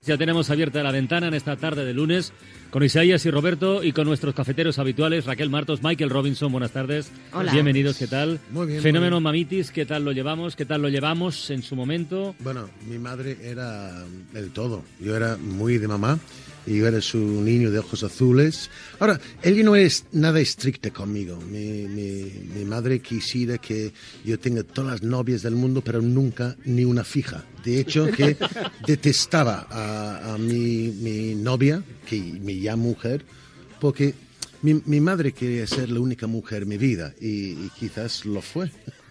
Primer dia d'emissió del programa amb la presentació de Carles Francino.
Entreteniment